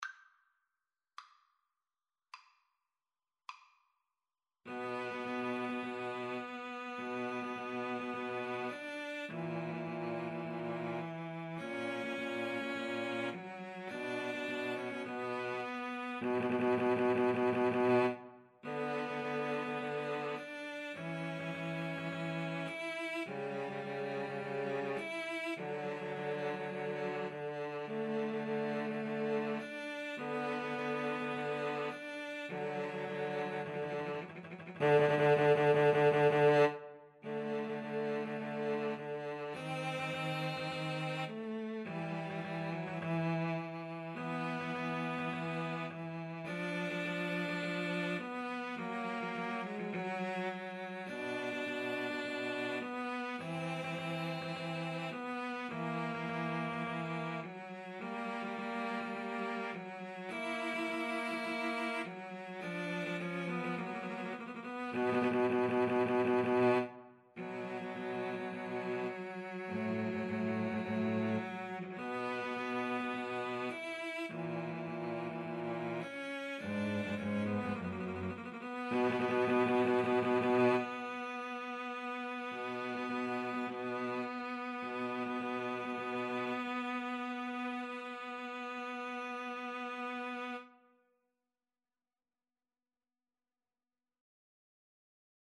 Free Sheet music for Cello Trio
B minor (Sounding Pitch) (View more B minor Music for Cello Trio )
Adagio = c. 52
Classical (View more Classical Cello Trio Music)